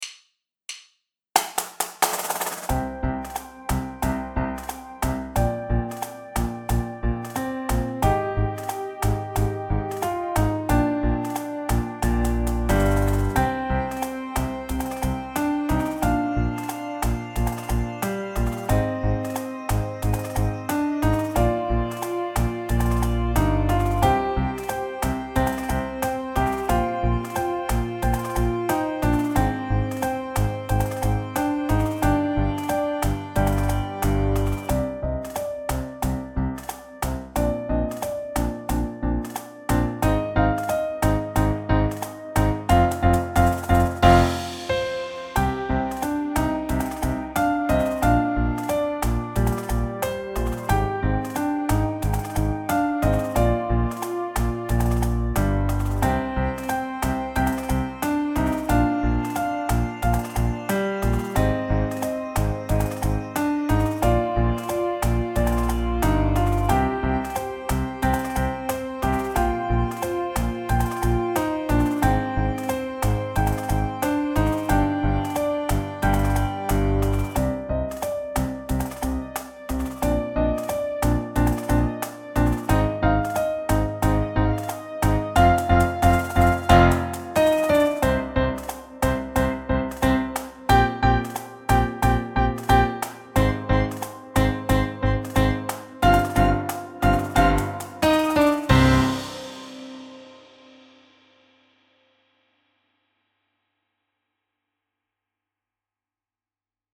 piano trio